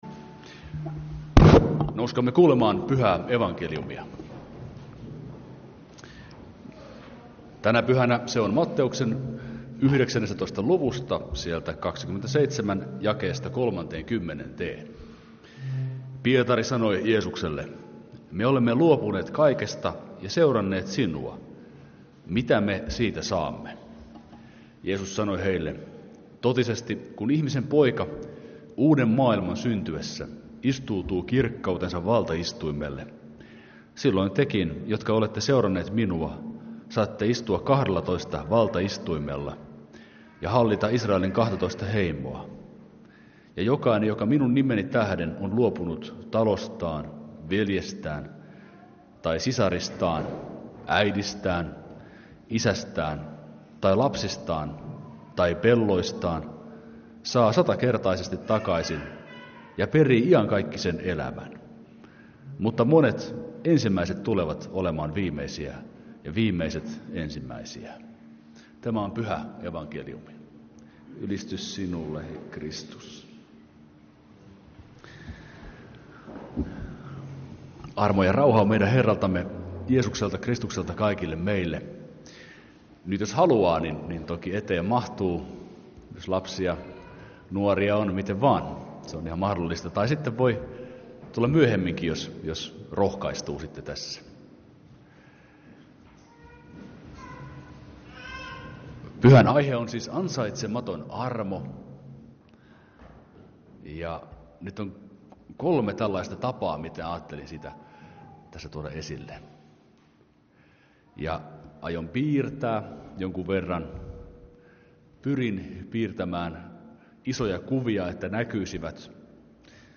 Perhemessun saarna.
Kokoelmat: Lahden lutherin kirkon saarnat